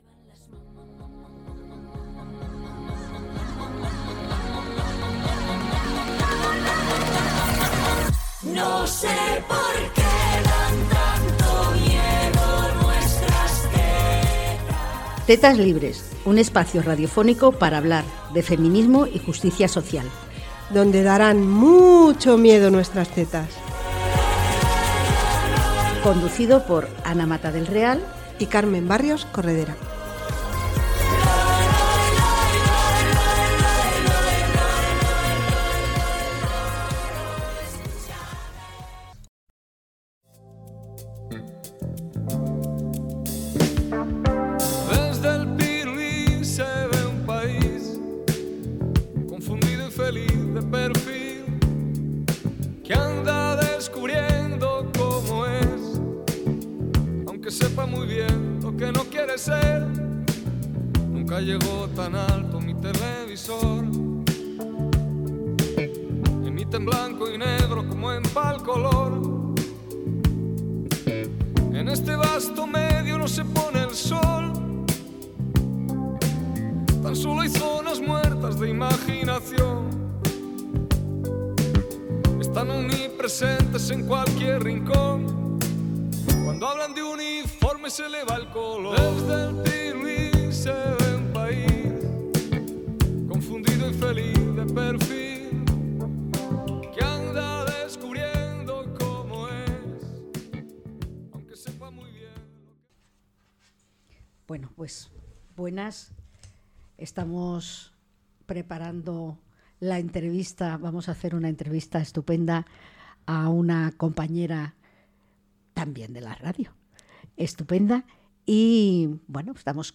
entrevistan